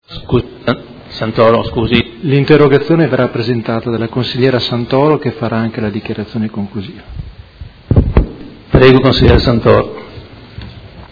Seduta del 26/09/2019. Interrogazione del Gruppo Consiliare Lega Modena avente per oggetto: Tutela dei minori in affido. Passa la parola alla consigliera Santoro